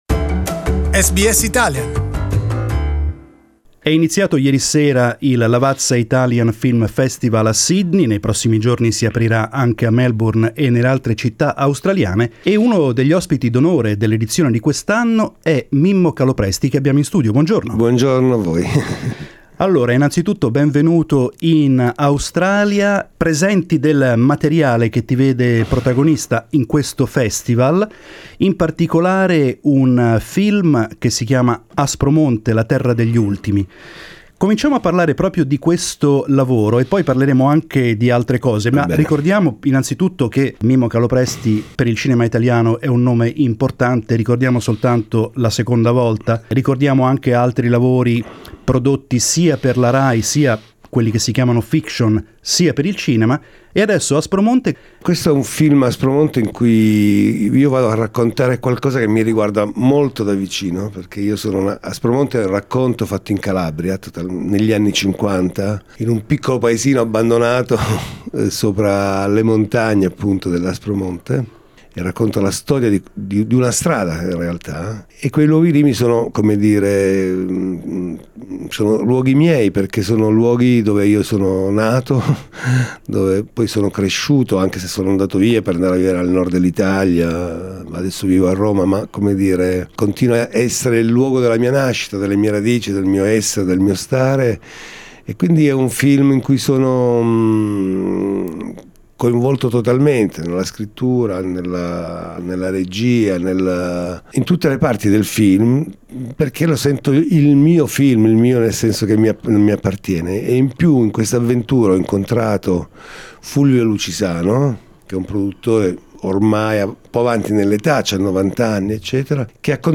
The director tells a story linked to his birthplace, Calabria, and in particular describes the reasons for a popular revolt set in the 1950s in the small mountain village of Africo. Mimmo Calopresti visited our studios and we asked him to tell us about the film, which is exceptionally screened in Australia even before its release in Italy.